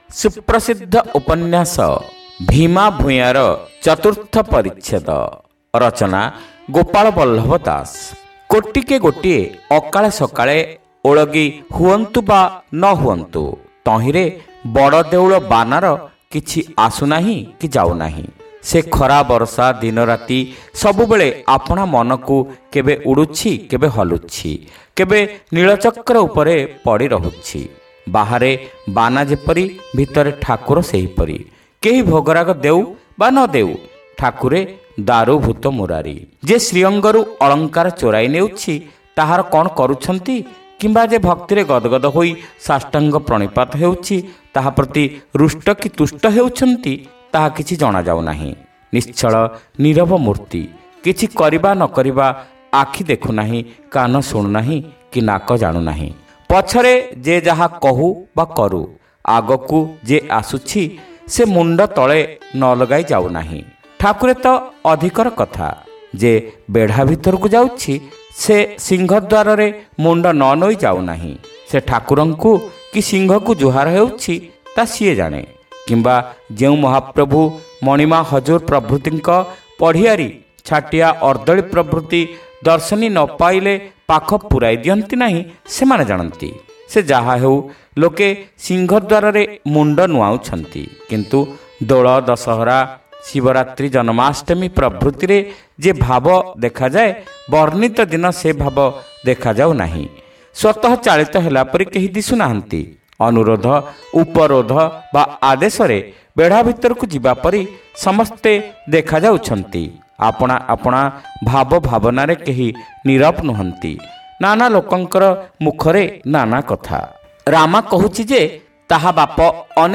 ଶ୍ରାବ୍ୟ ଉପନ୍ୟାସ : ଭୀମା ଭୂୟାଁ (ଚତୁର୍ଥ ଭାଗ)